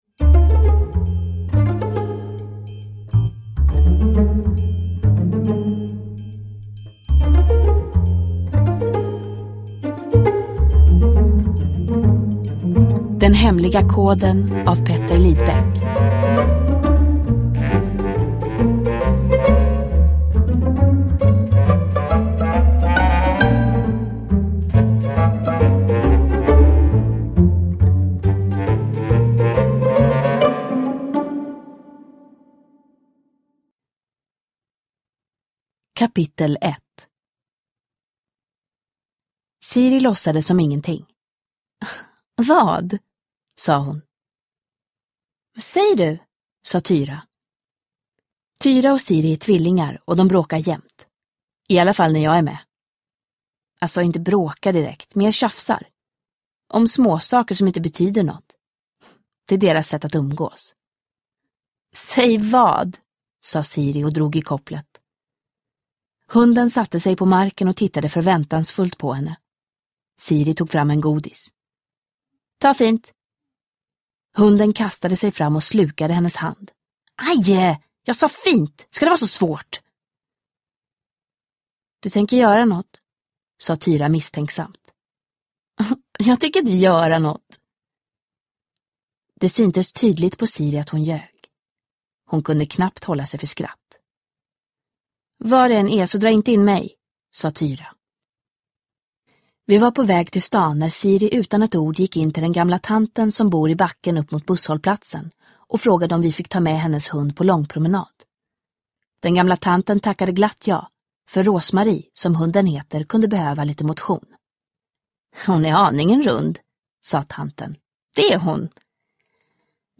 Den hemliga koden – Ljudbok – Laddas ner
Uppläsare: Mirja Turestedt